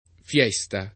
vai all'elenco alfabetico delle voci ingrandisci il carattere 100% rimpicciolisci il carattere stampa invia tramite posta elettronica codividi su Facebook fiesta [sp. f L%S ta ] s. f. — voce sp. («festa») usata in It. come nome commerciale, con pn. italianizz. [ f L$S ta ]